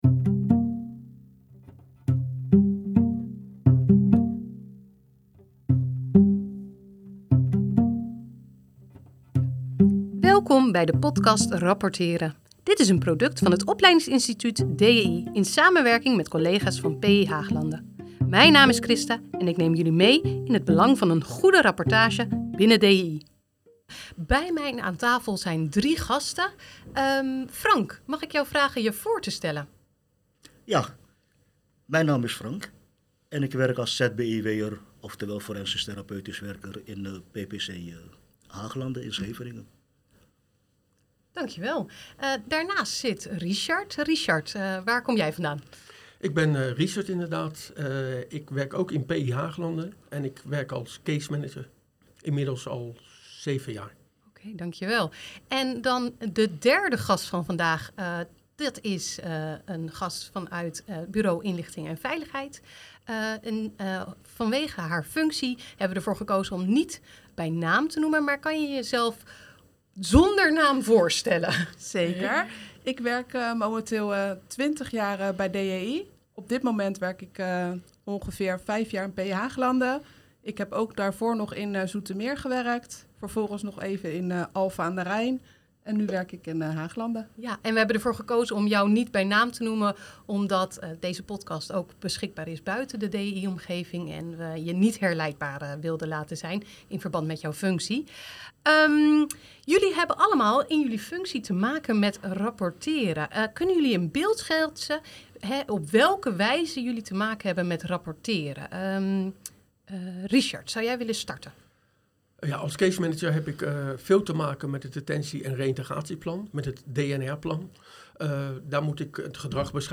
met drie DJI-medewerkers over het belang van rapporteren. Wat verstaan we eigenlijk onder een goed rapport?...